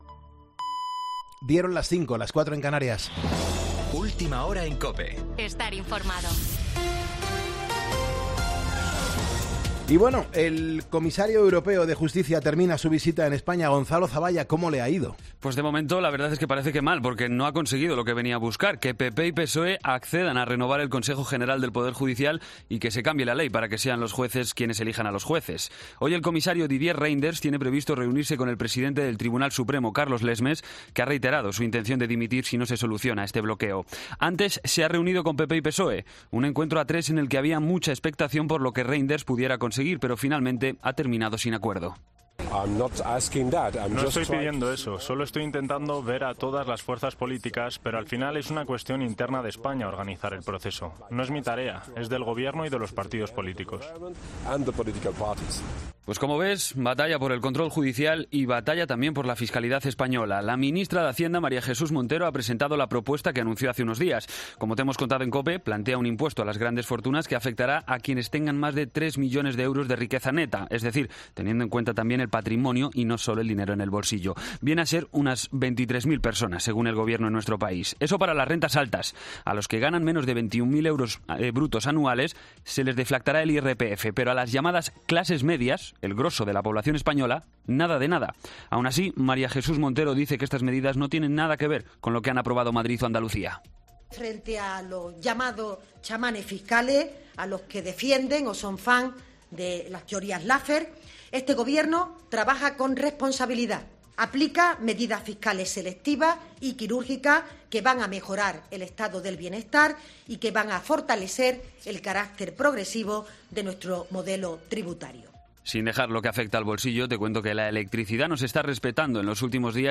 Boletín de noticias COPE del 30 de septiembre a las 05:00 hora
AUDIO: Actualización de noticias Herrera en COPE